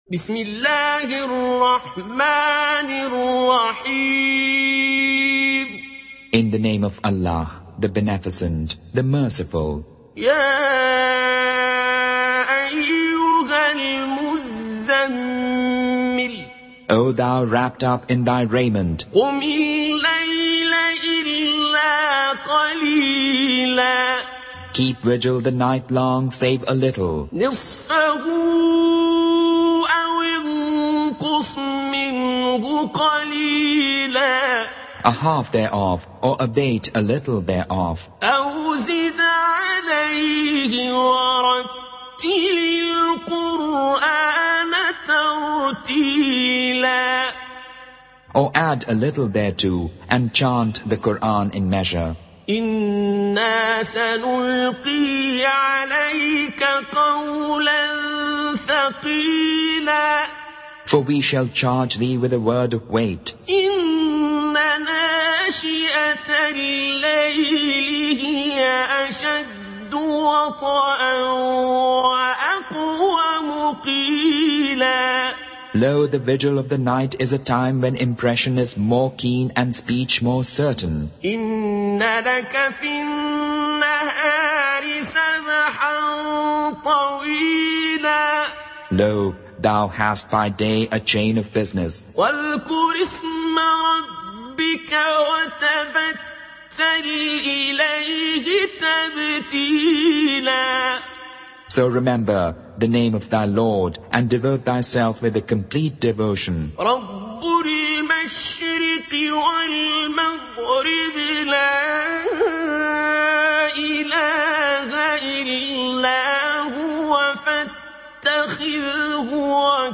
Surah muzammil listen to the mp3 audio recitations with the aid of Abd-ur Rahman As-Sudais and Suood As-Shuraim.
Voice: Abd-ur Rahman As-Sudais & Su'ood As-Shuraim, Urdu Tarjuma: Fateh Muhammad Jalandhari